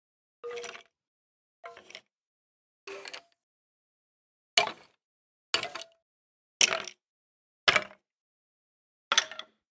PLAY skeleton sound effect
minecraft-skeleton-sounds.mp3